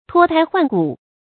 tuō tāi huàn gǔ
脱胎换骨发音
成语正音胎，不能读作“tái”。